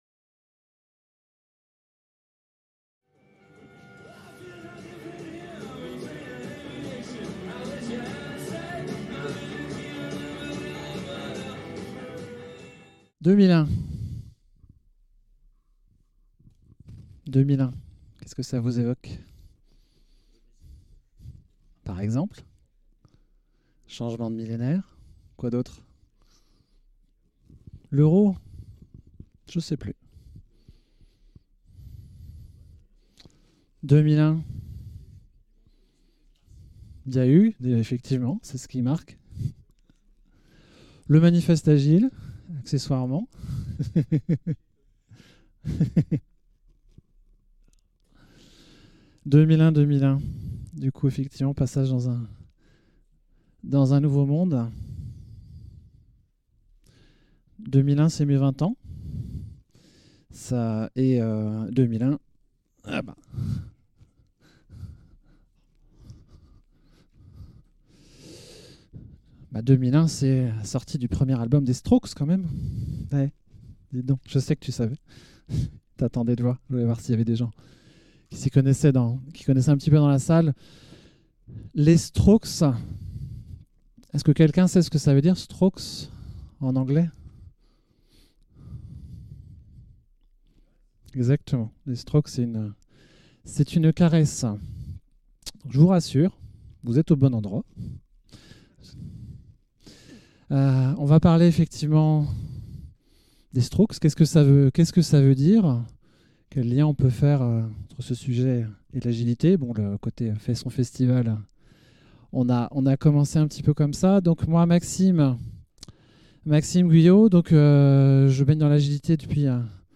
Le Printemps agile 2024 fait son festival